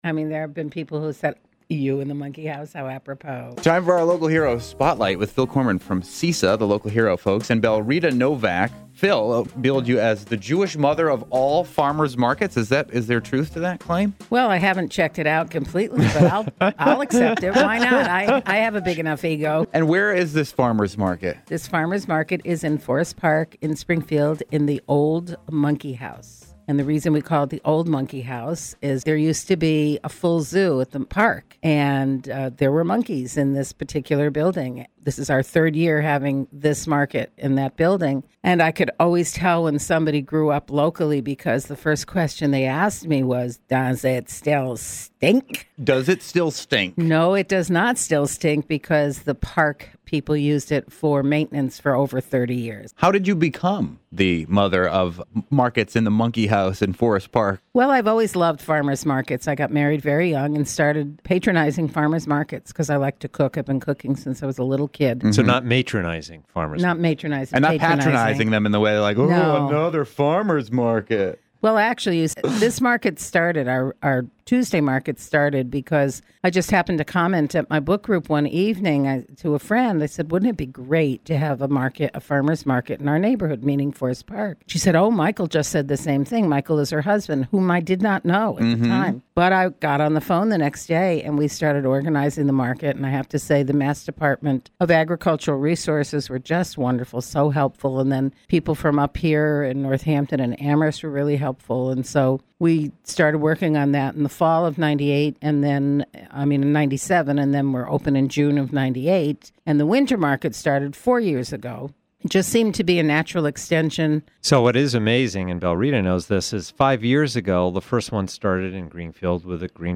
Interview: Springfield Winter Farmers’ Market